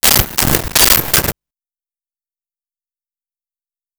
Clock Loop
Clock_loop.wav